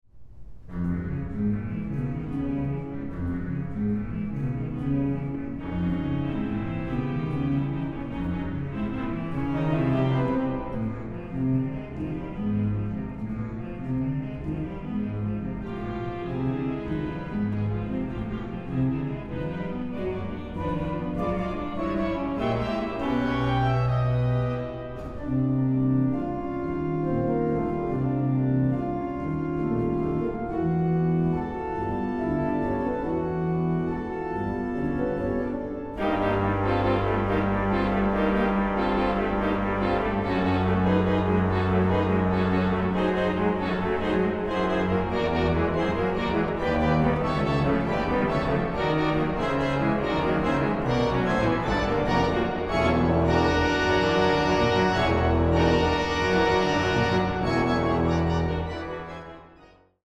Orgel
Aufnahme: Het Orgelpark, Amsterdam, 2023